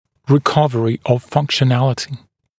[rɪ’kʌvərɪ əv ˌfʌŋkʃə’nælɪtɪ][ри’кавэри ов ˌфанкшэ’нэлити]восстановление функциональности